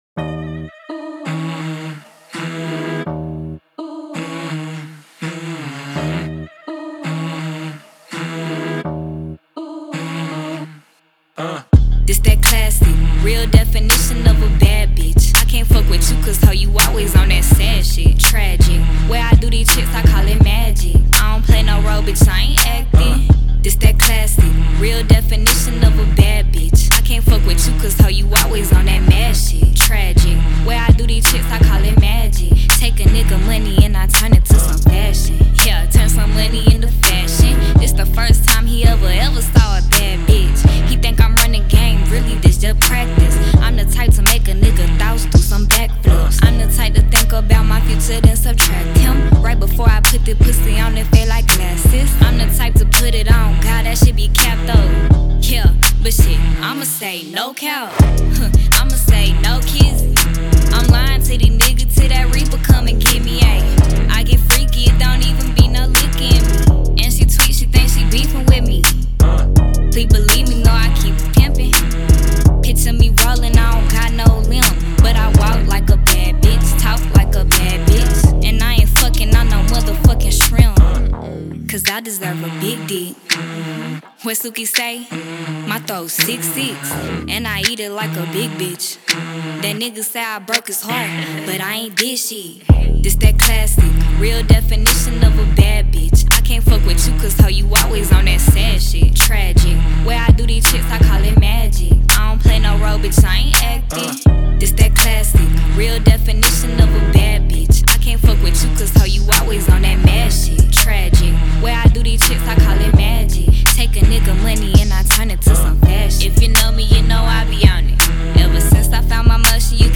• Жанр: Rap, Hip-Hop